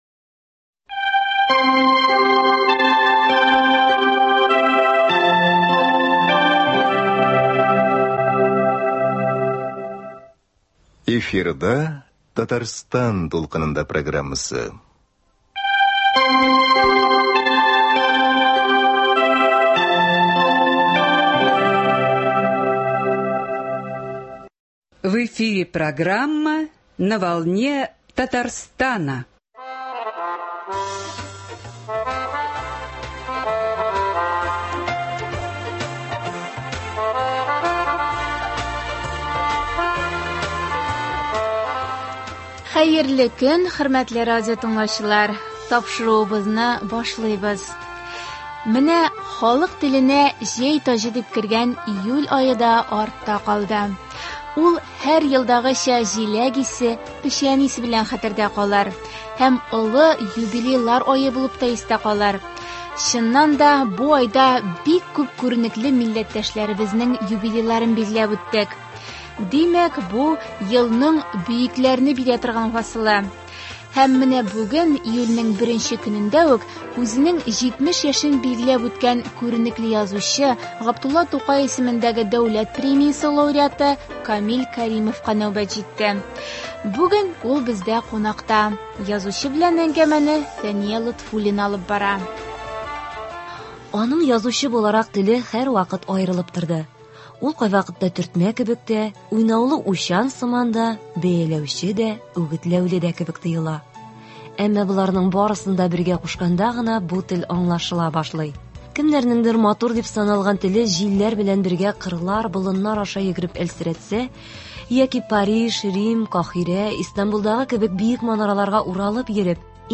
язучы белән әңгәмәне